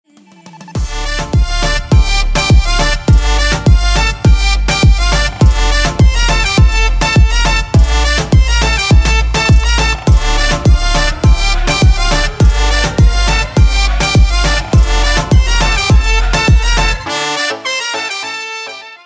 • Качество: 320, Stereo
поп
зажигательные
dance
Гармошка
Зажигательная румынская поп-музыка